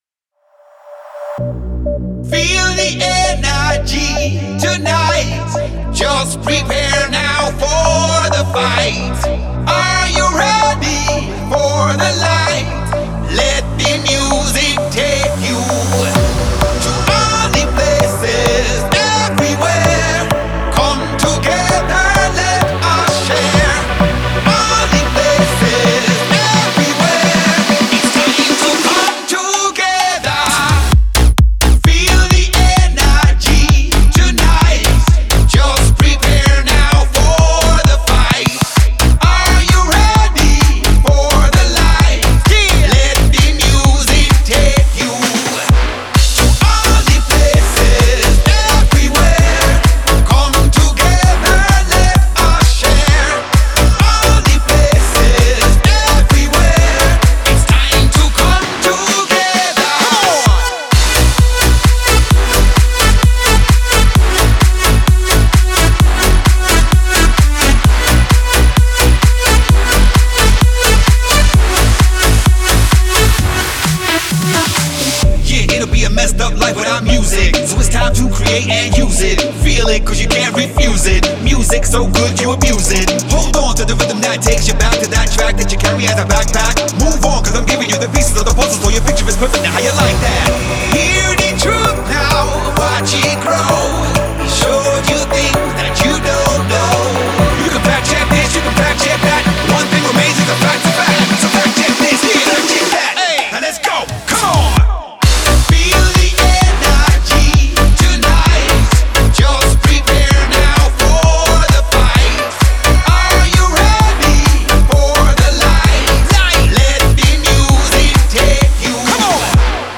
это динамичная электронная композиция